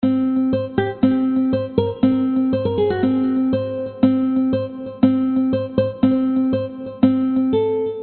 描述：以120 bpm的速度弹奏古典吉他曲
Tag: 原声 经典 作曲家 吉他 器乐 循环